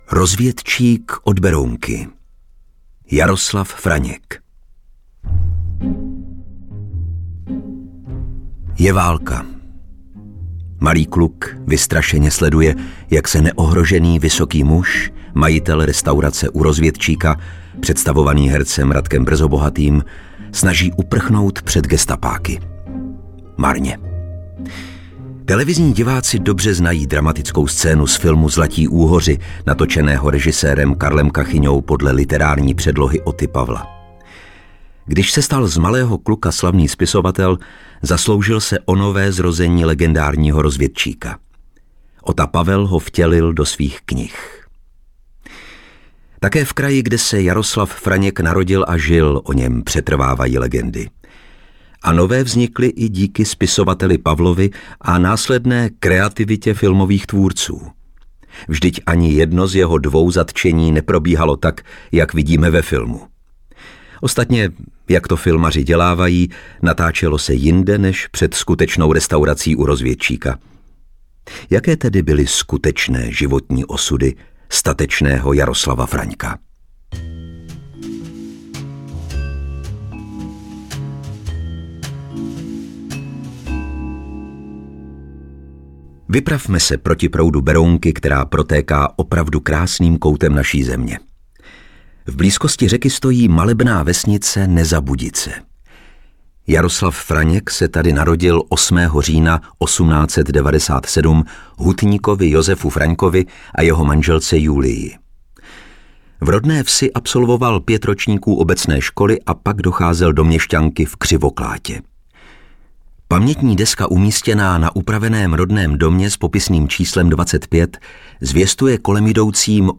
audiokniha
Čte: Lukáš Hlavica